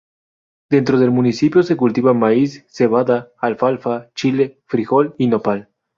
Pronounced as (IPA)
/noˈpal/